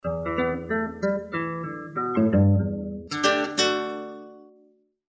描述：前奏/后奏为dubtrancetriphop蓝调音乐。
Tag: 100 bpm Blues Loops Guitar Electric Loops 3.23 MB wav Key : Unknown Ableton Live